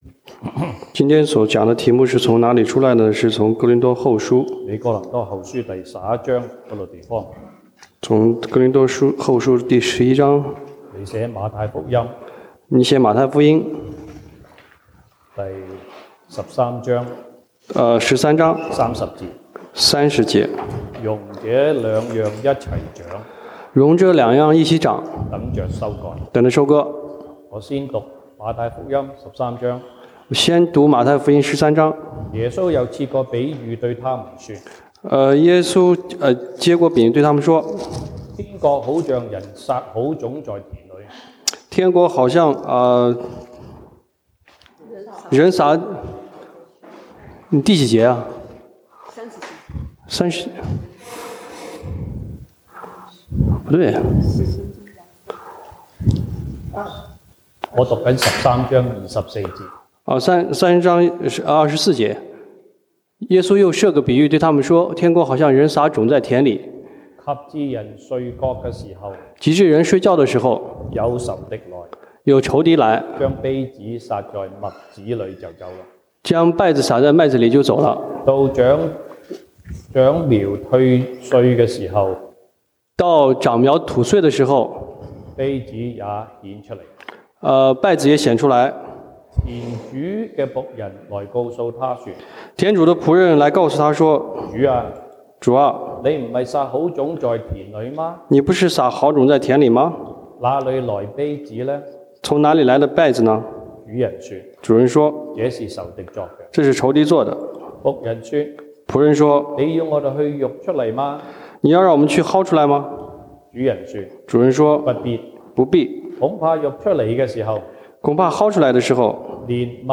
Passage: 歌林多後書 2 Corinthians 11:1-15 Service Type: 西堂證道(粵語/國語) Sunday Service Chinese